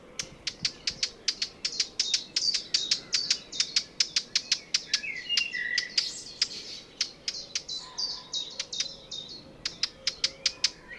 XC651380-toutinegra-de-barrete-Sylvia-atricapilla